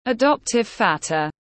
Bố nuôi tiếng anh gọi là adoptive father, phiên âm tiếng anh đọc là /əˈdɒp.tɪv ˈfɑː.ðər/.